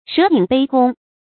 蛇影杯弓 注音： ㄕㄜˊ ㄧㄥˇ ㄅㄟ ㄍㄨㄙ 讀音讀法： 意思解釋： 把酒杯中的弓影當成了蛇。